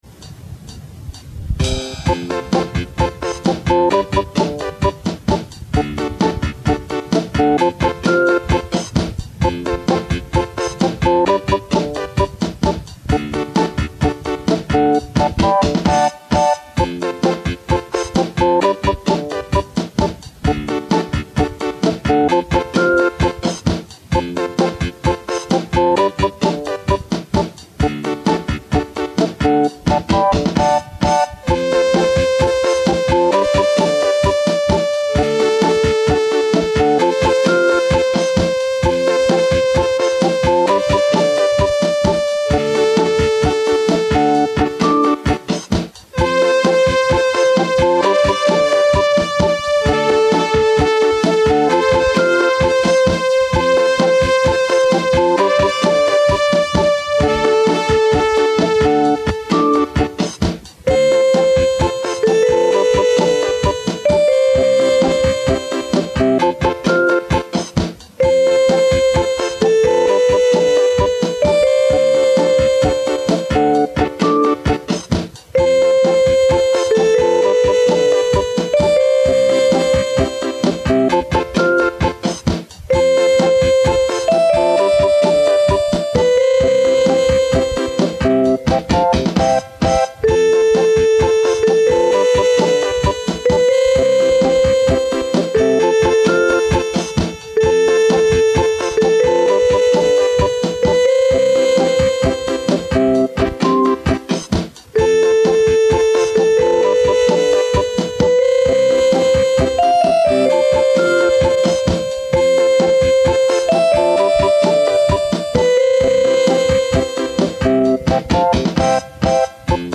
If you don’t like it in the first minute or so, the next four minutes aren’t going to do anything new to change your mind.